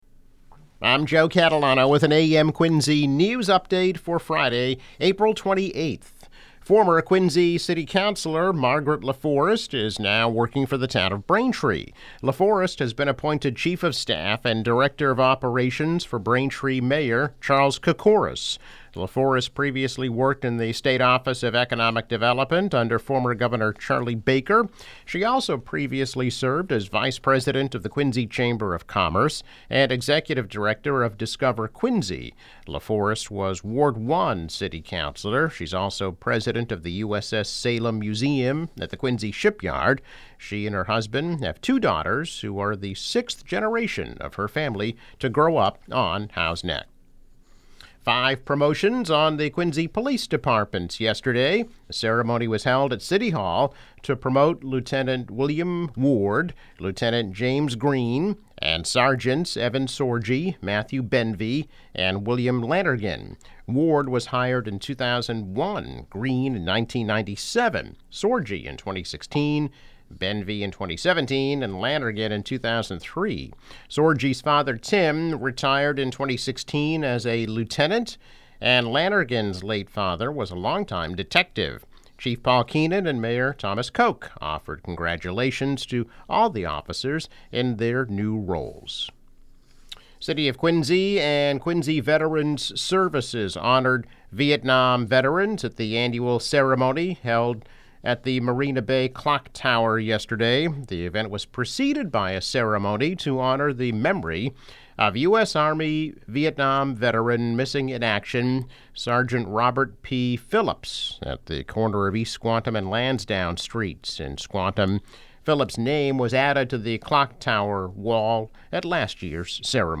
Daily news, weather and sports update.